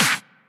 clap.ogg